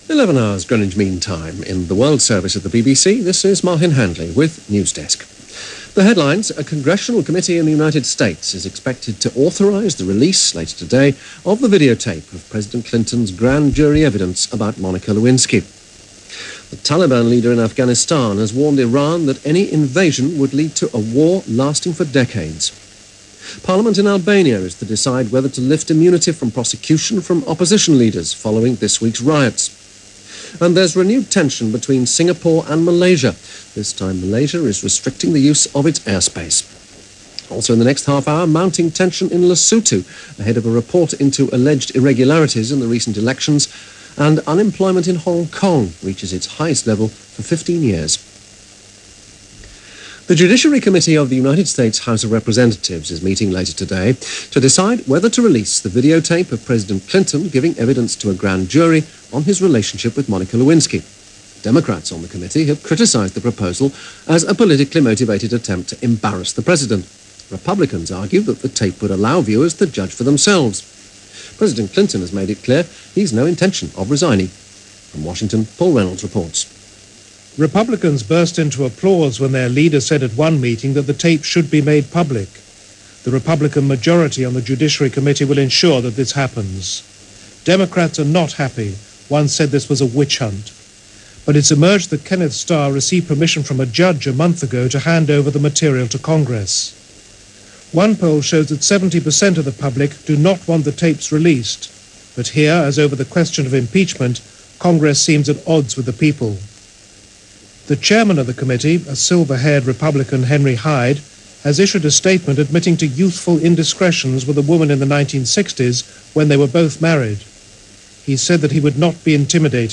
September 17, 1998 - Releasing the Testimony - The Iranian-Afghan Scuffle - Hong Kong Unemployment skyrockets - news for this day in 1998.